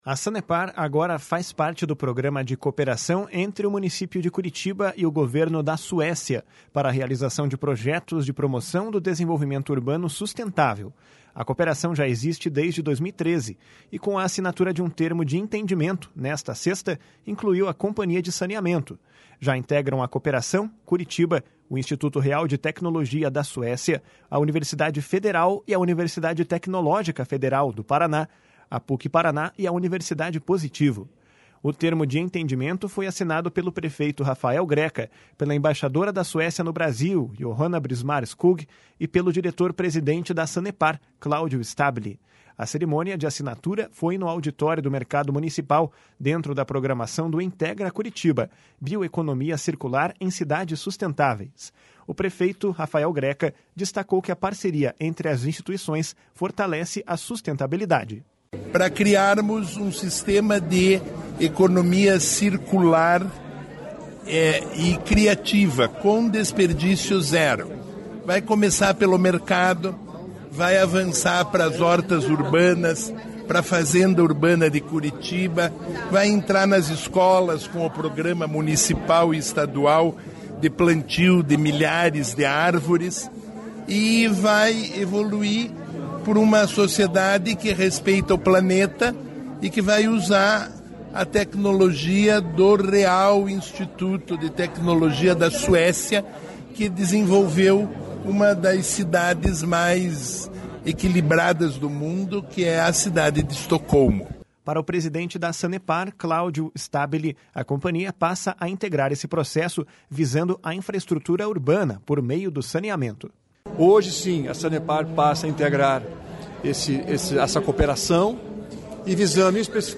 O prefeito Rafael Greca destacou que a parceria entre as instituições fortalece a sustentabilidade. // SONORA RAFAEL GRECA //